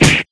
Blow1.ogg